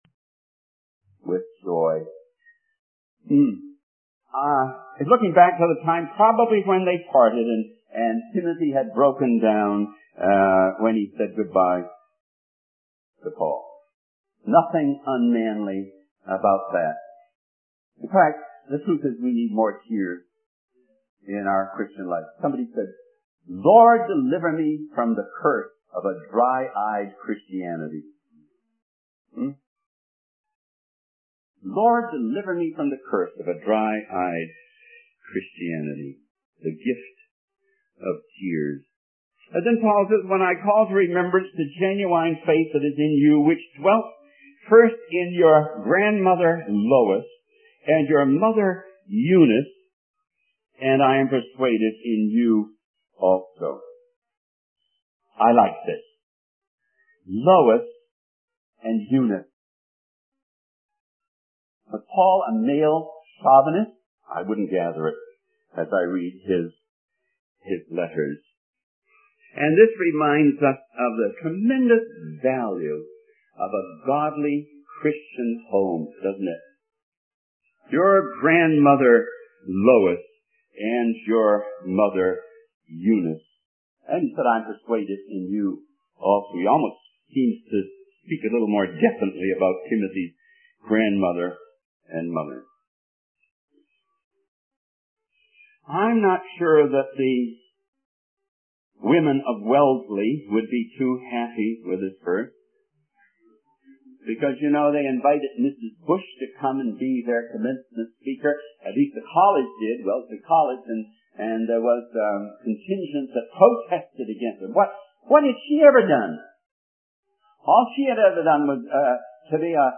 In this sermon, the preacher discusses the spiritual lesson found in the progression of music notes from F sharp to F natural to F flat.
The preacher encourages attendees to recharge their spiritual batteries at the conference and not be discouraged by the challenges they may face in their service to God. He also highlights the power of Jesus' sacrifice and the assurance of eternal life through the gospel.